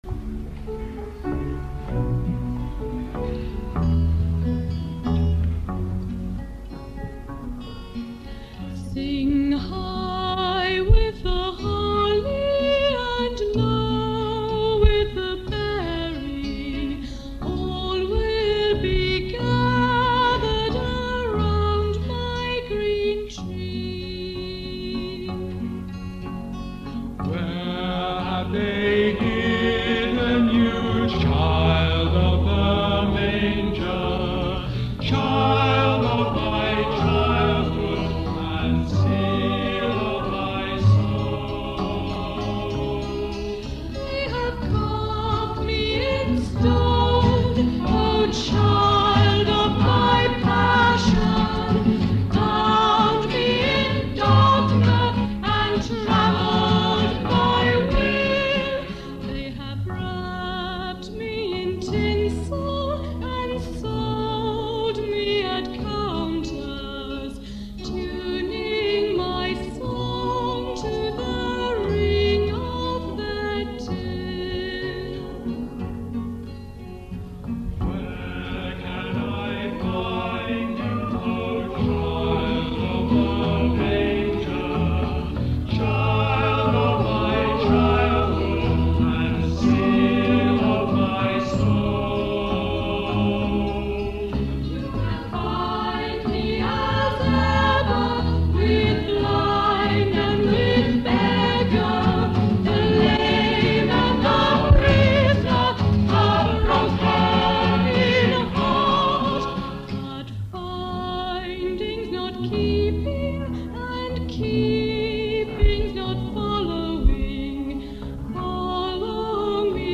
A striking contemporary carol